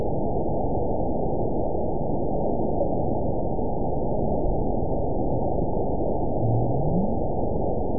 event 914249 date 05/02/22 time 04:14:18 GMT (3 years ago) score 9.64 location TSS-AB02 detected by nrw target species NRW annotations +NRW Spectrogram: Frequency (kHz) vs. Time (s) audio not available .wav